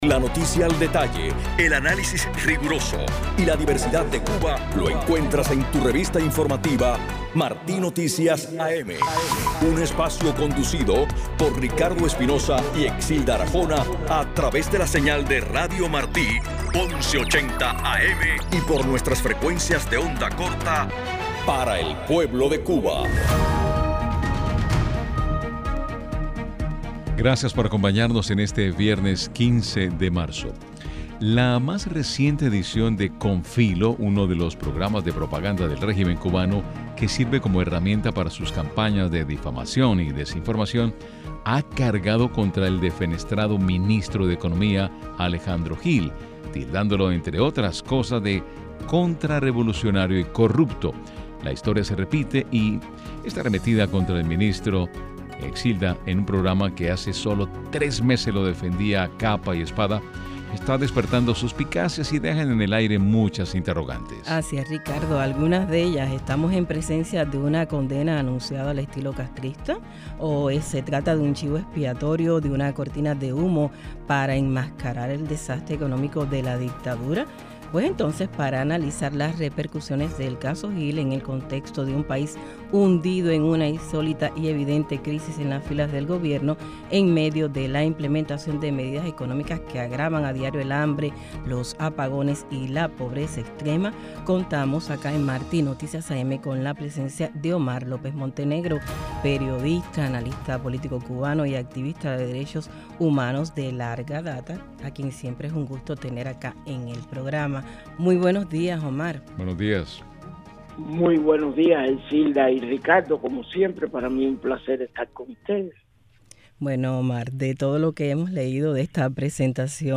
Durante la entrevista para la revista informativa Martí Noticias AM el experto comparó lo ocurrido con Gil con los tiempos de Cronos, el dios griego que según la leyenda se alimentaba de sus hijos para alcanzar la eternidad.